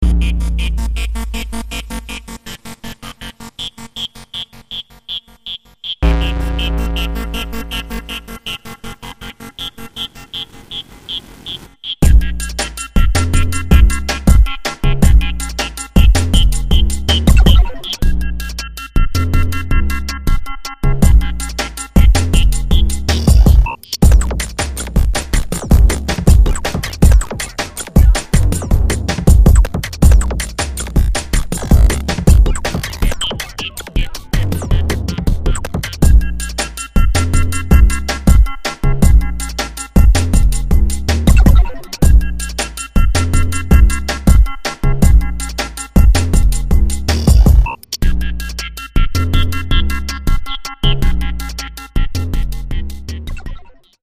Original Music From the Soundtrack
A cutting edge electronica masterpiece